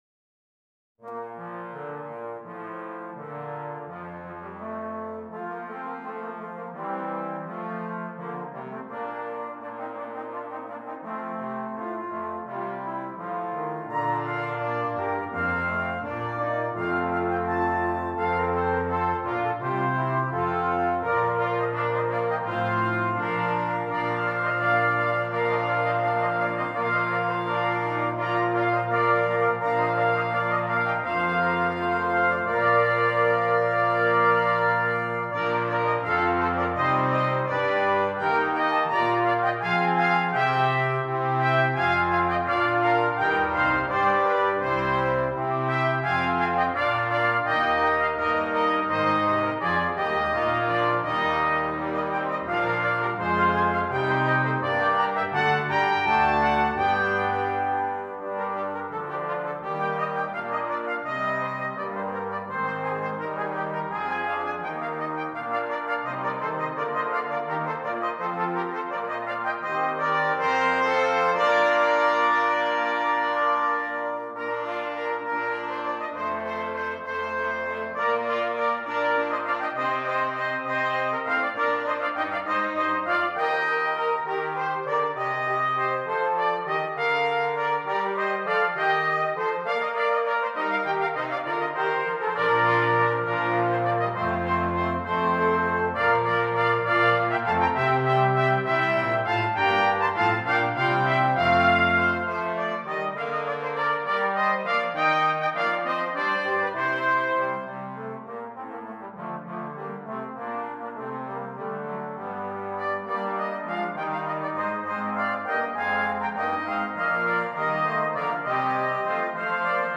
Ten Piece Brass Ensemble